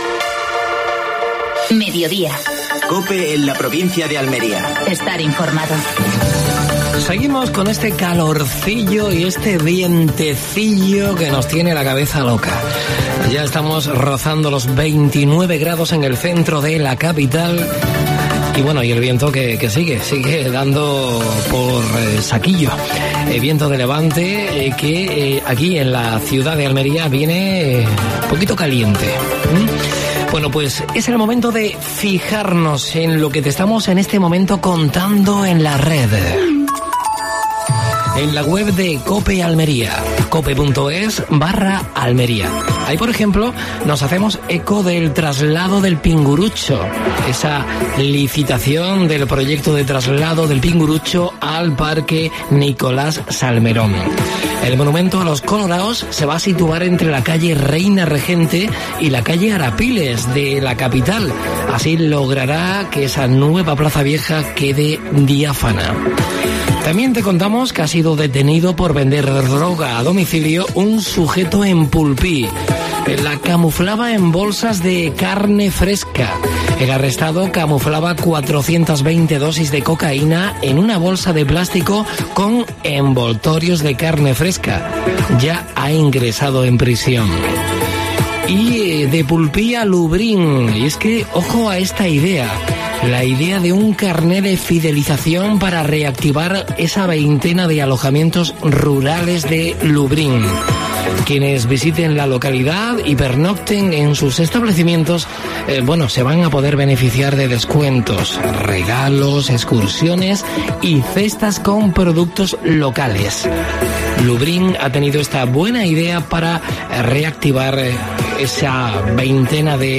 AUDIO: Actualidad en Almería. Entrevista a Fernando Giménez (diputado de Presidencia de la Diputación Provincial de Almería).